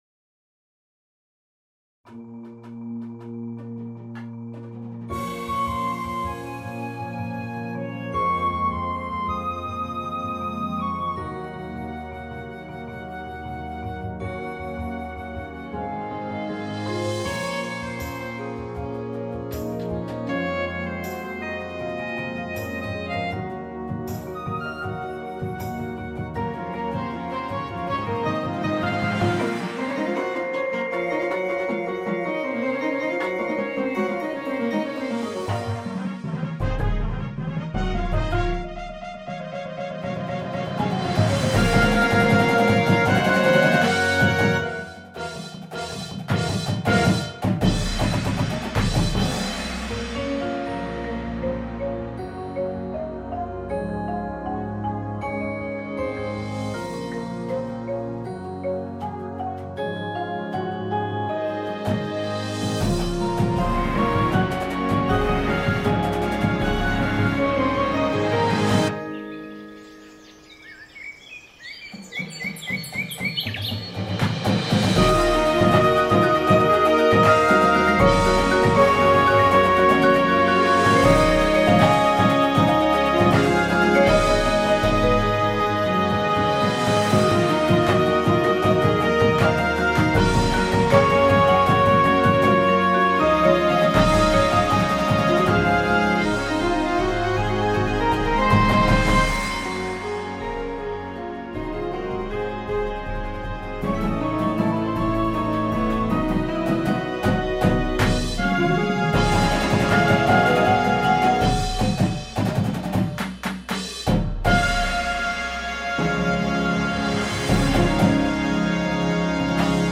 • Flute
• Trumpet 1
• Tuba
• Snare Drum
• Bass Drums